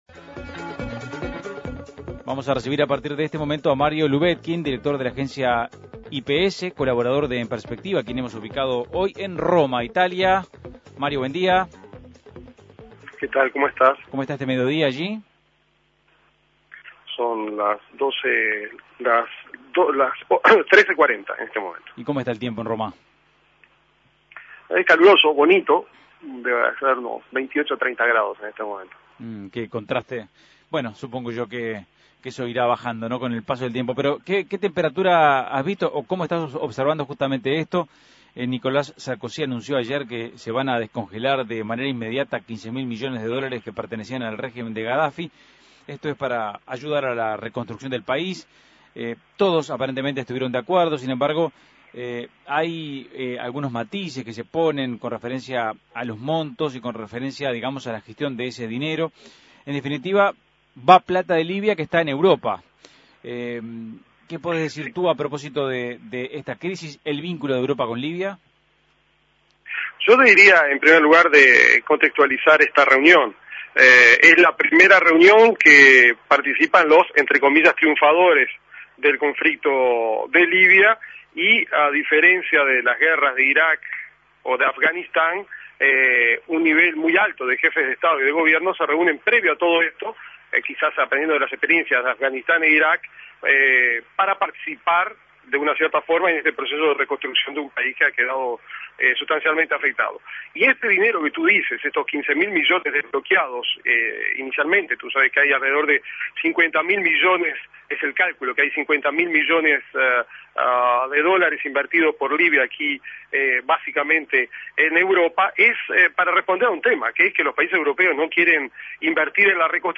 Escuche el contacto con Mario Lubetkin, colaborador de En Perspectiva